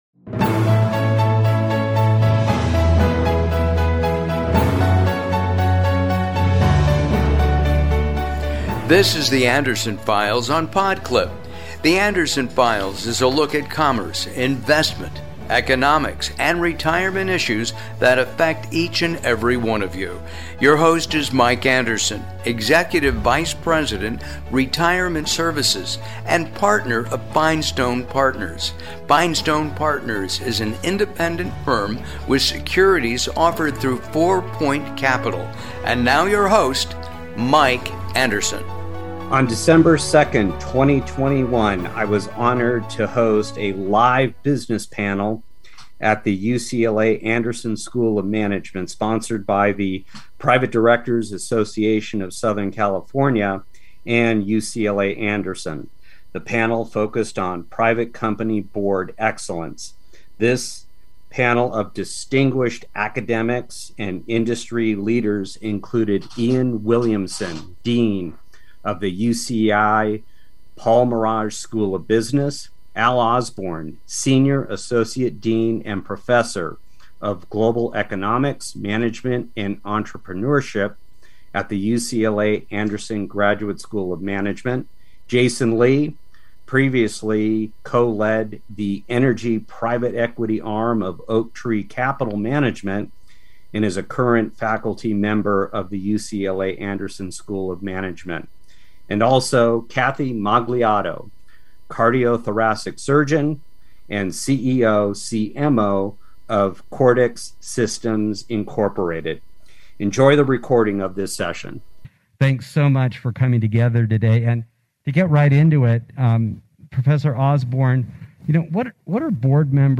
This episode is a recording of the December 2nd event.